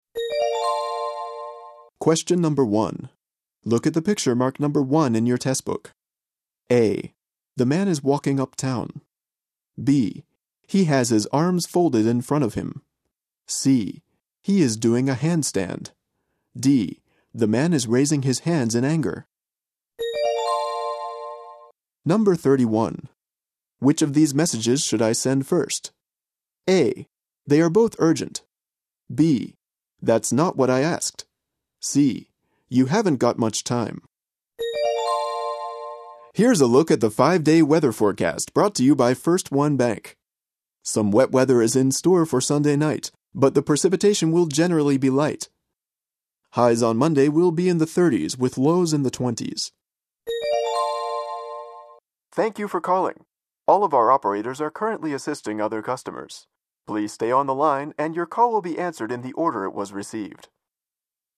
英語/北米・オセアニア地方 男性